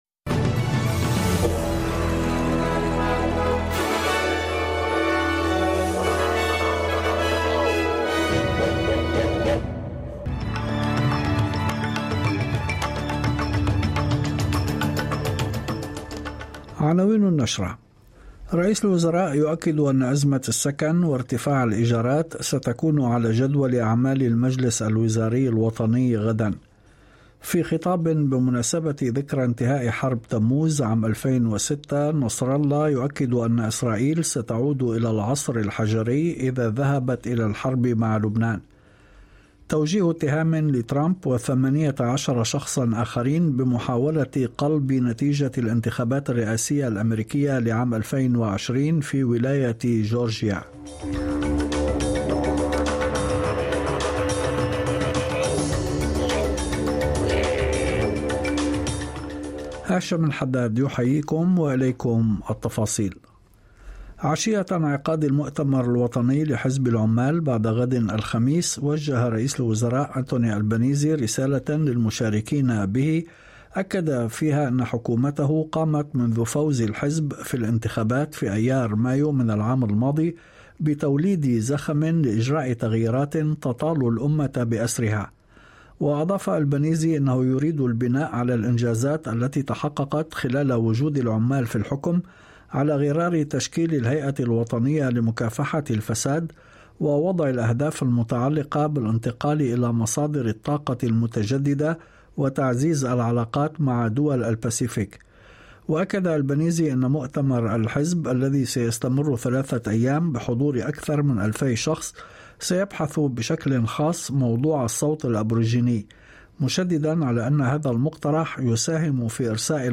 نشرة أخبار المساء 15/08/2023